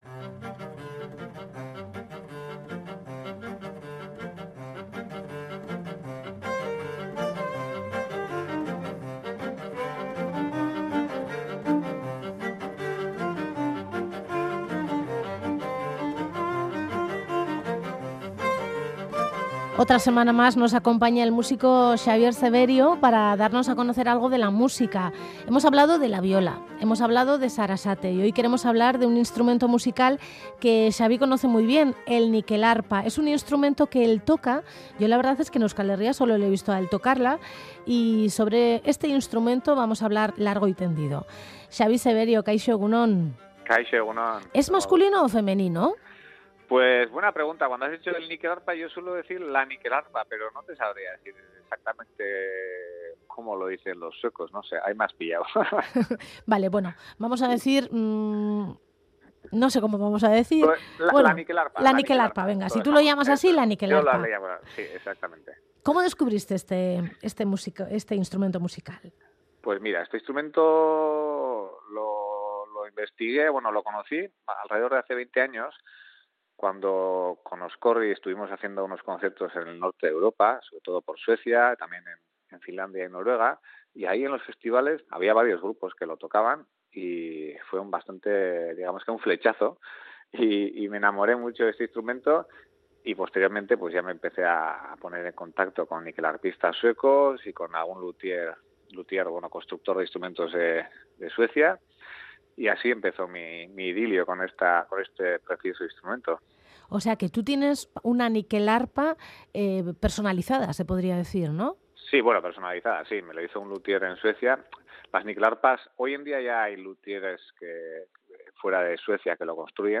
Nyckelharpa: un instrumento musical de cuerda con teclas y un sonido télurico